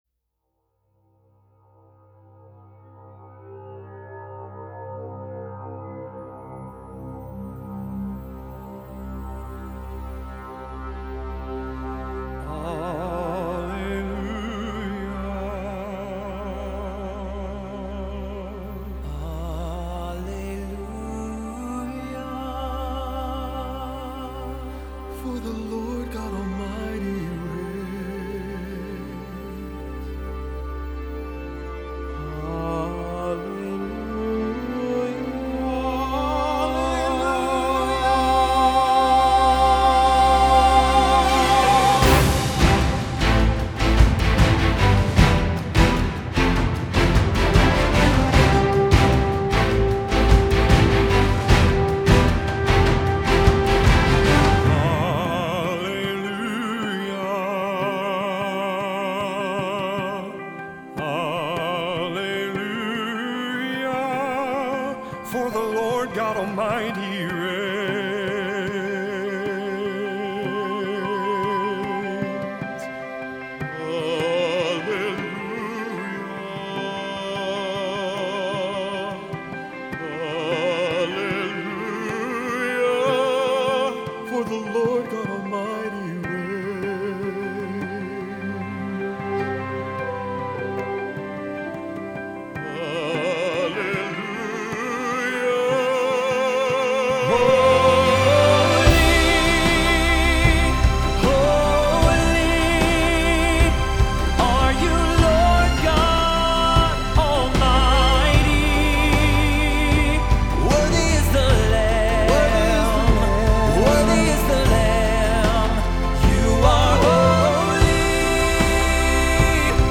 contemporary classical vocal group